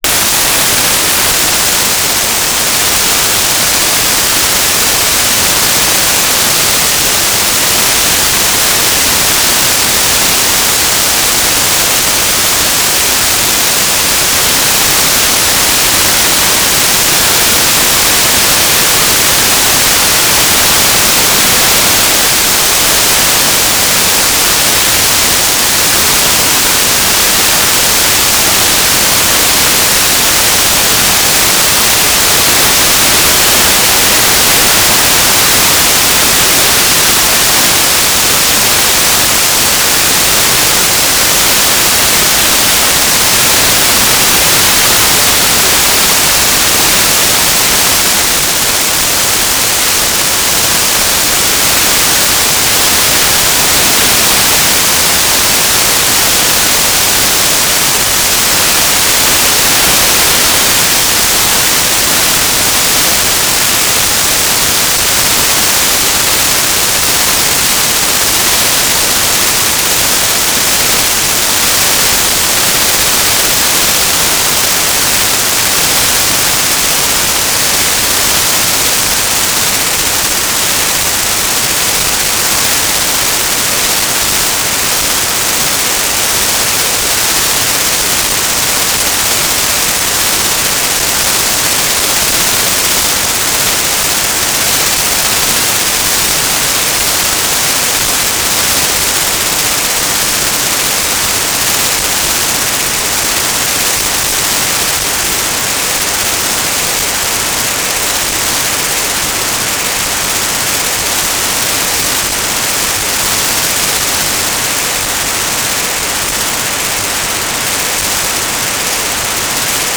"transmitter_description": "Telemetry",
"transmitter_mode": "FM",